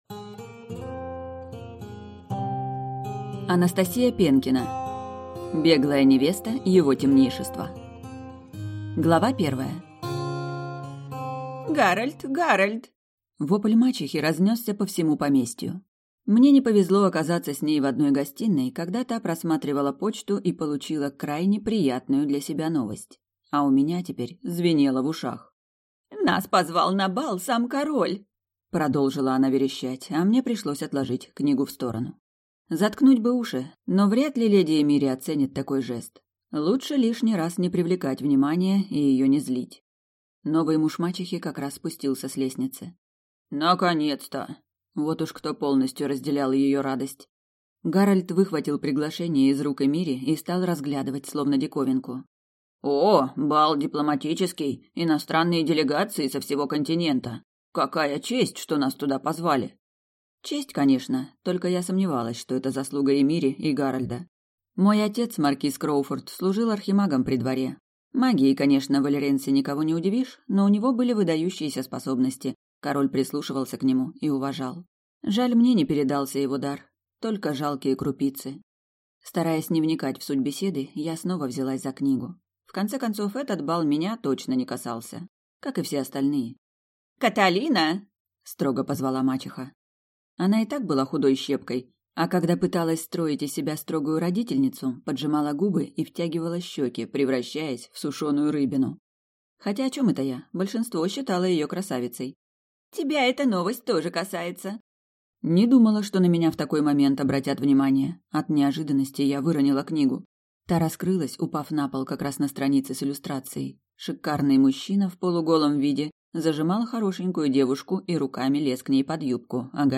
Аудиокнига Беглая невеста его темнейшества | Библиотека аудиокниг